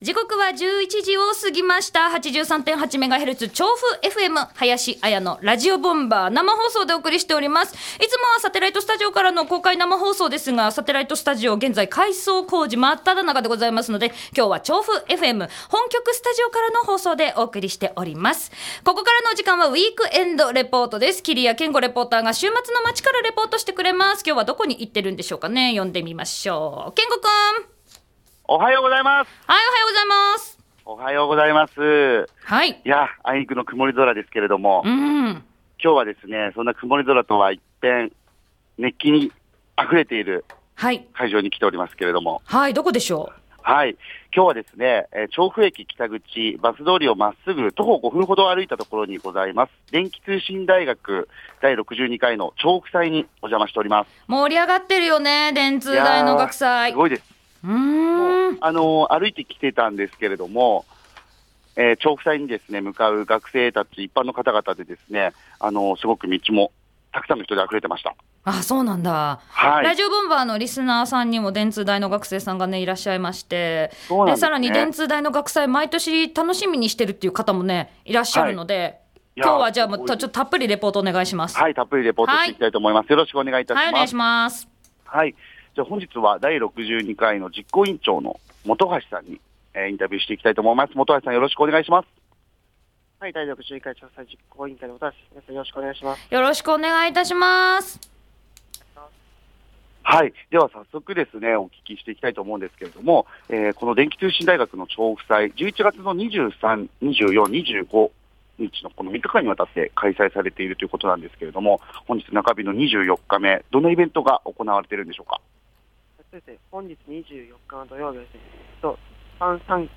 学園祭シーズー真っ只中！ ということで、11/24のウィークエンドレポートは、今年で第62回をむかえる電気通信大学、調布祭にお邪魔しました！
会場は、大学生の元気いっぱいの熱気で盛り上がっていました♪ 日替りで様々なイベントが開催されており、 中日の本日のイベントというと、クイズ大会やビンゴ大会、アカペラ大会、イライラ棒等、イベントが盛り沢山！